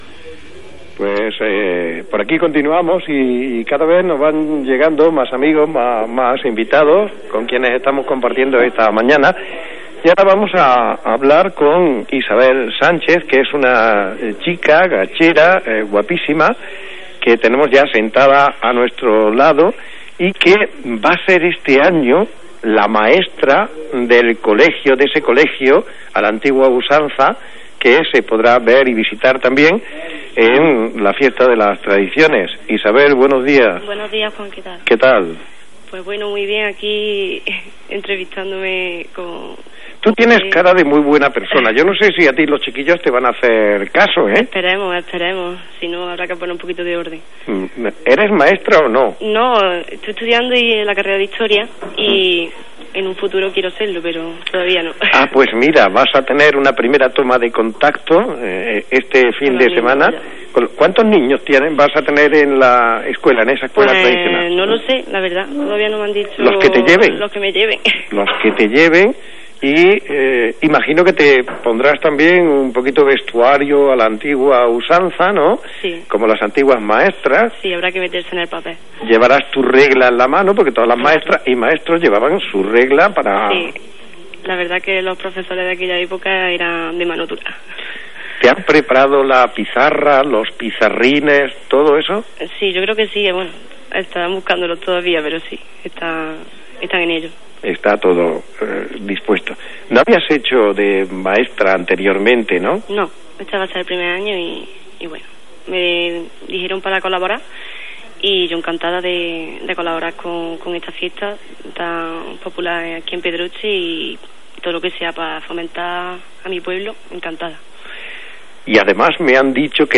Entrevistas realizadas por RADIO LUNA SER, el 13 de abril, en un programa especial realizado desde Pedroche.